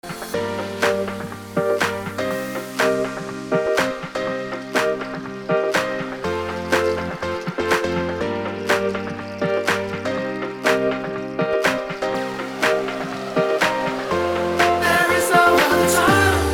• Качество: 256, Stereo
красивые
спокойные
инди рок
Легкий инди-рок